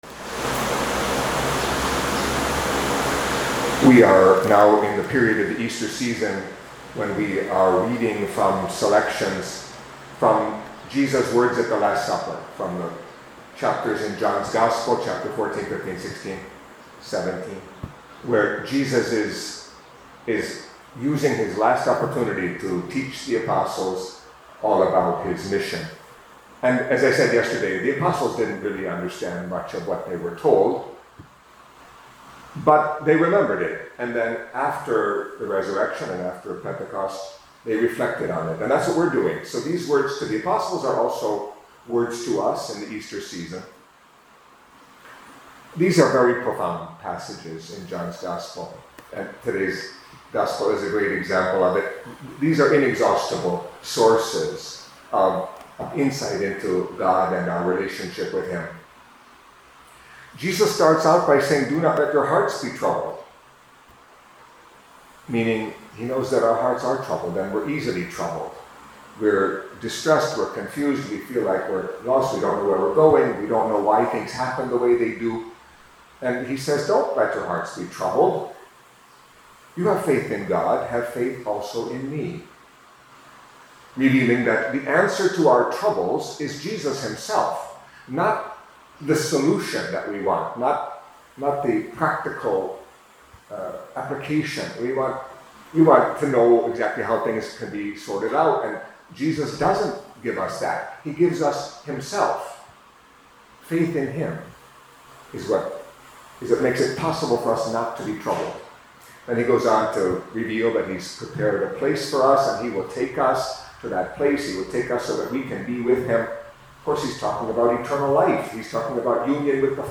Catholic Mass homily for Friday of the Fourth Week of Easter